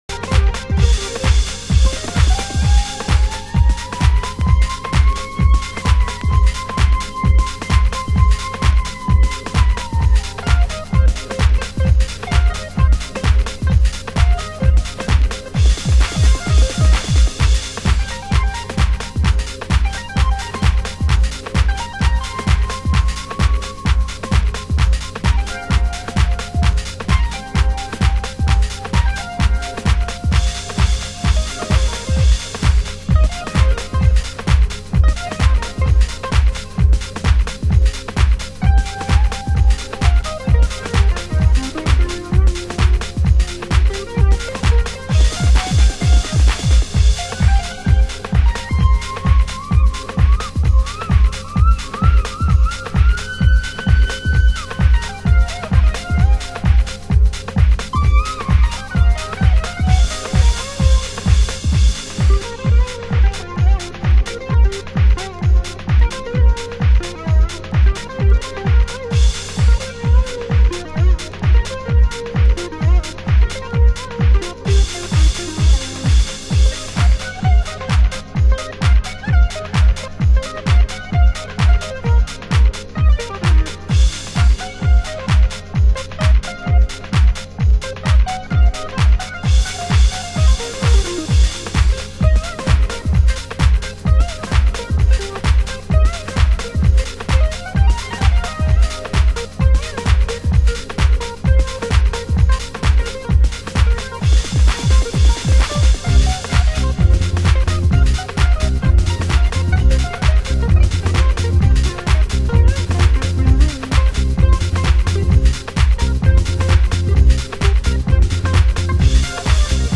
盤質：ヘアラインのスレ/小傷による少しチリパチノイズ有